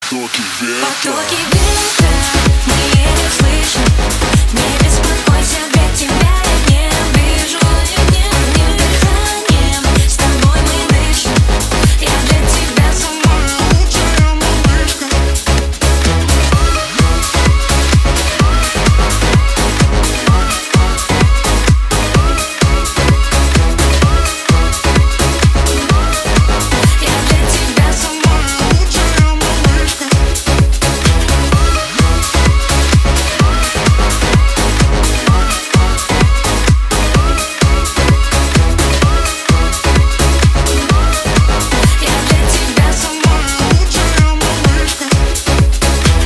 • Качество: 320, Stereo
dance
club
house